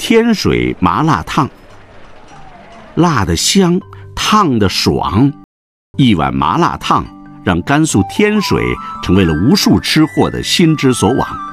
Natürliche KI-Stimme eines Healthy-Lifestyle-Bloggers für Wellness-Inhalte
Erleben Sie eine beruhigende, motivierende KI-Stimme, die für Wellness-Storytelling, Ernährungsbildung und ganzheitliche Lifestyle-Inhalte entwickelt wurde.
Achtsames Tempo
Empathischer Ton
Sie verfügt über natürliche Atemmuster und eine ruhige Kadenz, was sie ideal für lange Erzählungen oder kurze tägliche Affirmationen macht.